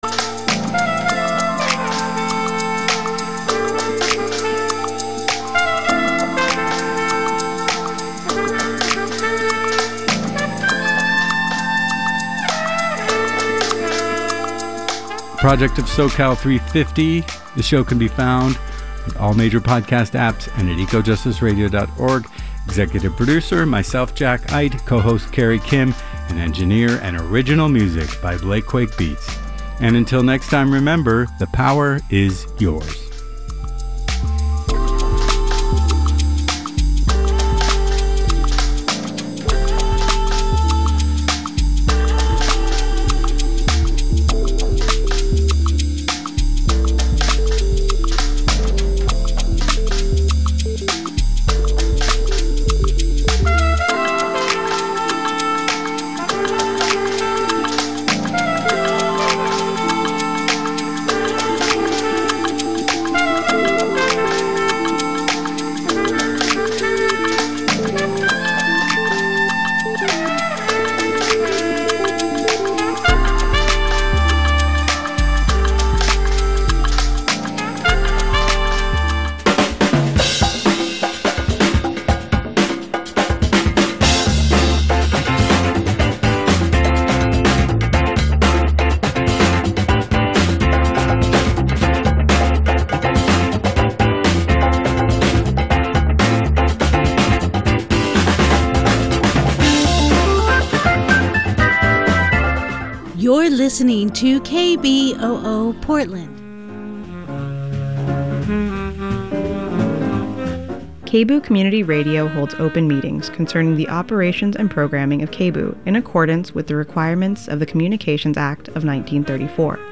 New guest interviews as well happen from time to time.